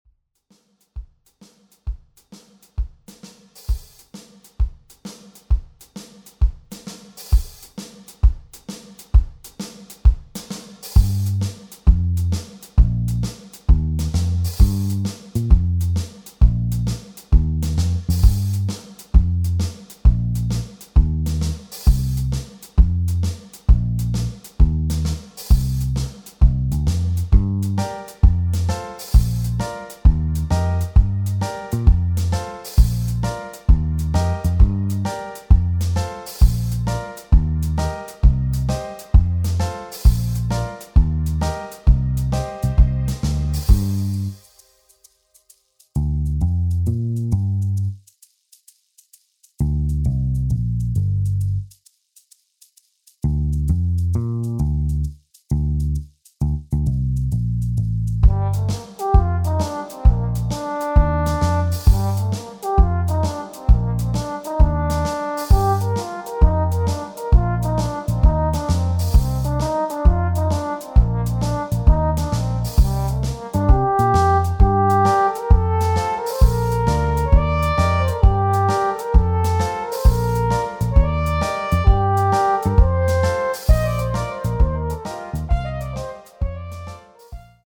Tonart: Gm Solopart French Horn (Originaltonart)
Art: Bandversion
Das Instrumental beinhaltet NICHT die Leadstimme